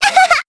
Dosarta-Vox_Happy2_jp.wav